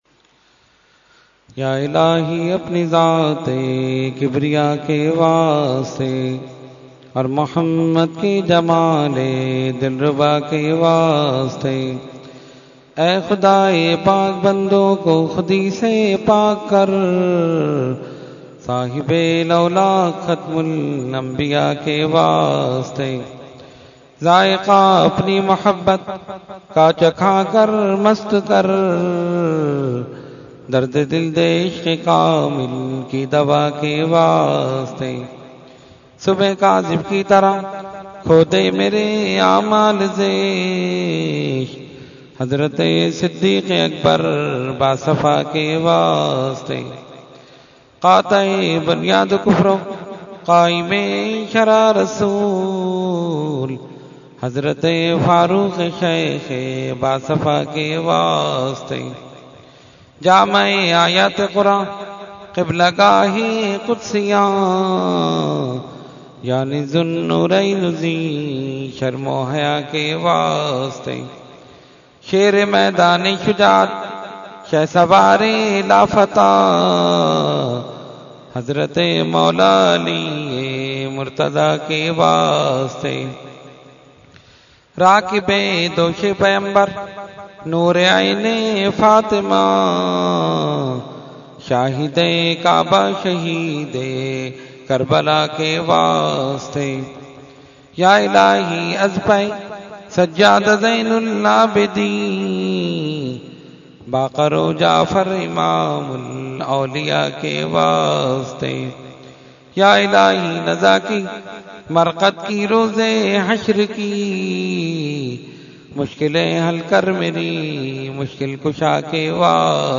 Dua – Shab e Baraat 2016 – Dargah Alia Ashrafia Karachi Pakistan
Category : Dua | Language : UrduEvent : Shab e Baraat 2016